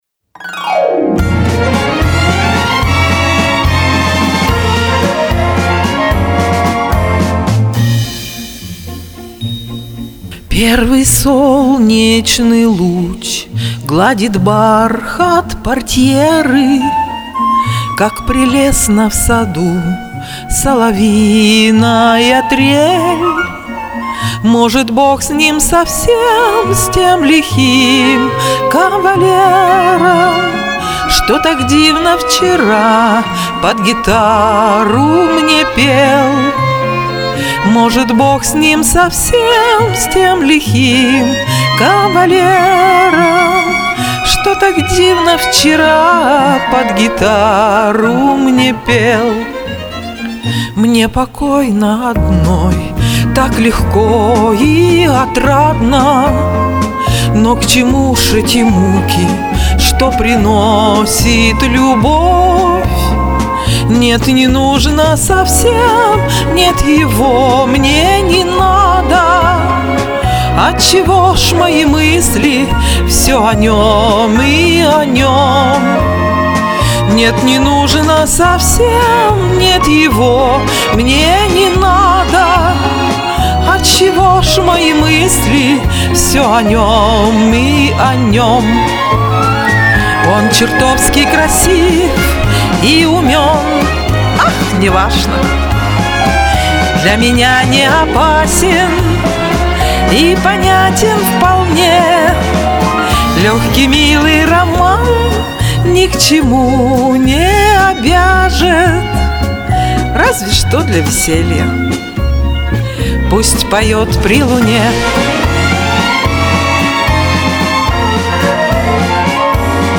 Этот вальс мне очень нравится.